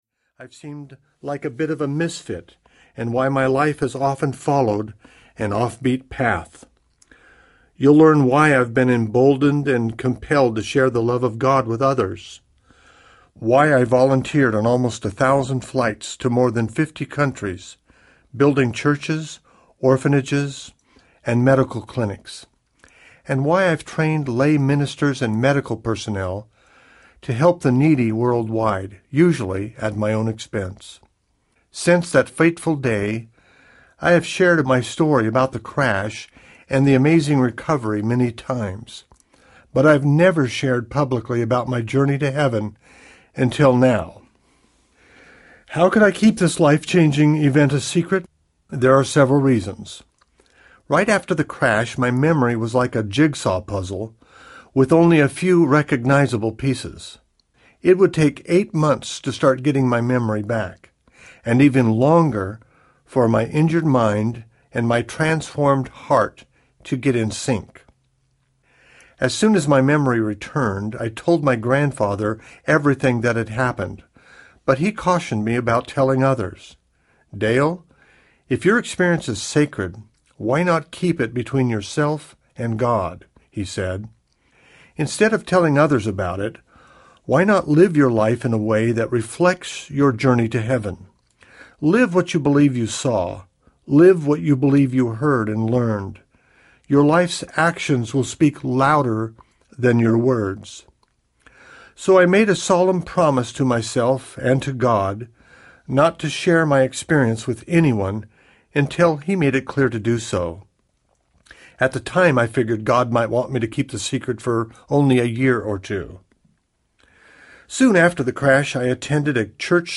Flight to Heaven Audiobook